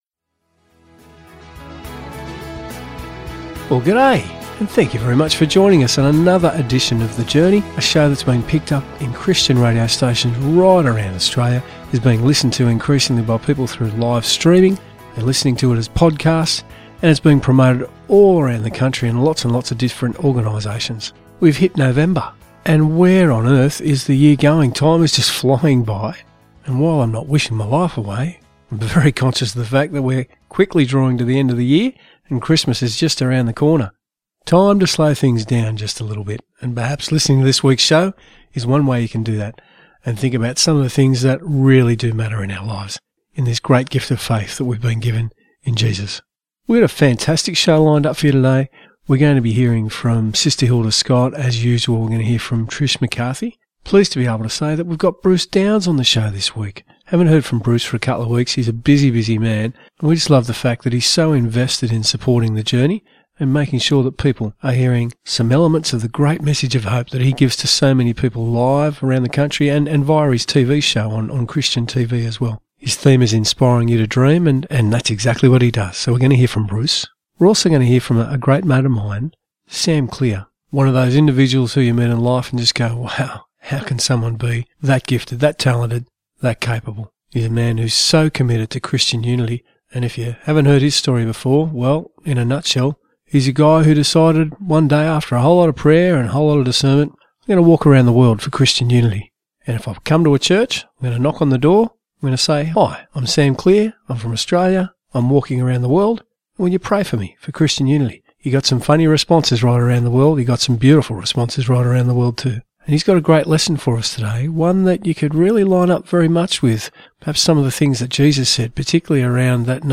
"The Journey" is a weekly Christian Radio program produced by the Catholic Diocese of Wollongong and aired on Christian Radio Stations around the country.